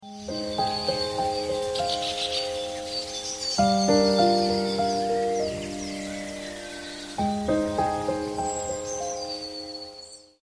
A calm journey across water sound effects free download